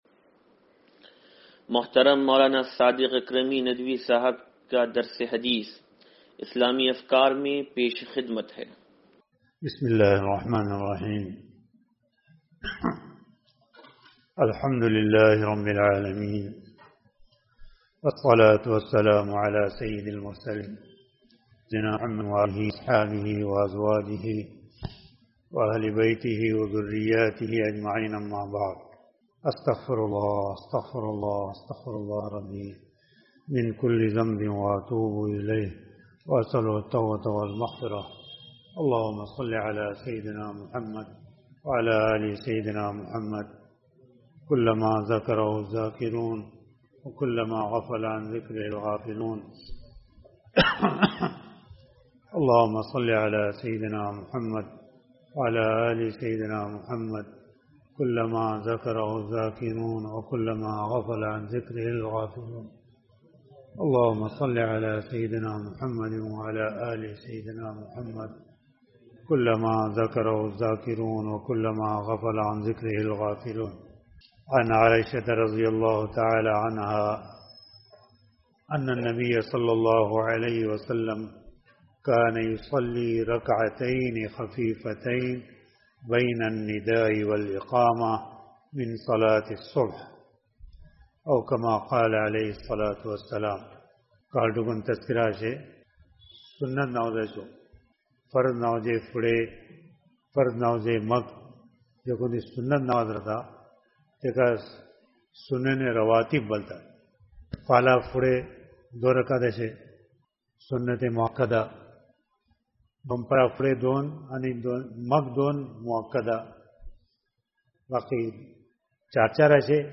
درس حدیث نمبر 0827